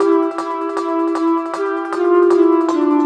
Track 11 - Tone Bells 02.wav